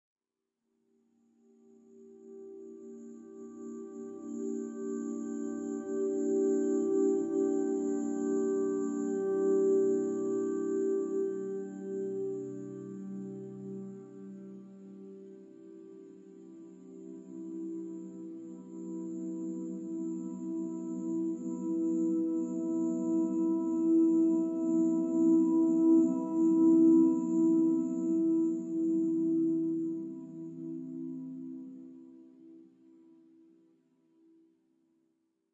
Bronson Canyon in Griffith Park.